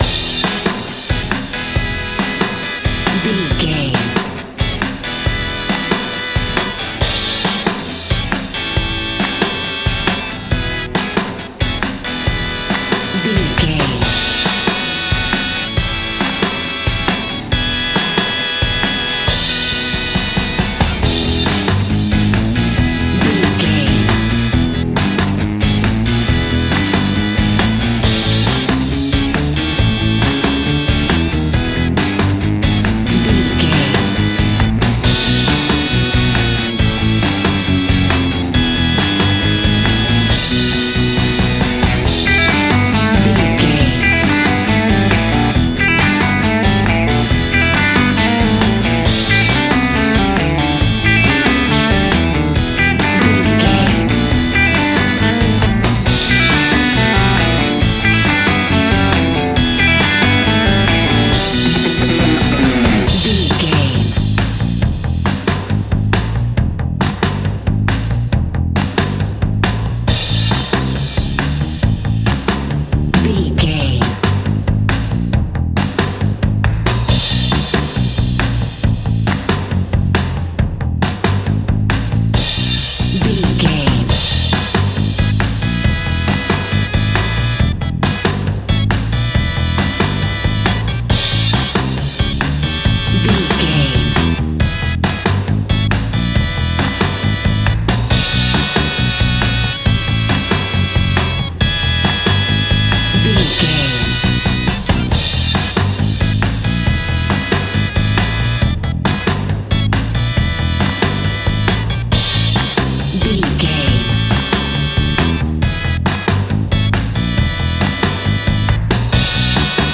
Ionian/Major
70s
pop rock
energetic
uplifting
catchy
upbeat
acoustic guitar
electric guitar
drums
piano
organ
bass guitar